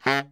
Index of /90_sSampleCDs/Giga Samples Collection/Sax/BARITONE DBL
BARI  FF C#2.wav